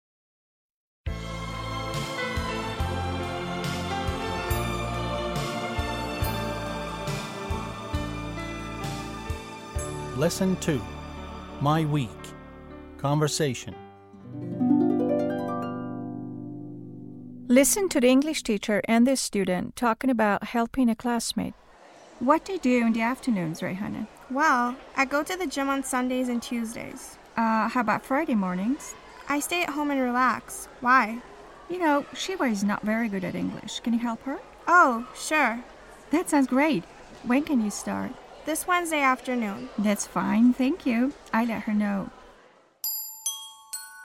8-L2-Conversation.mp3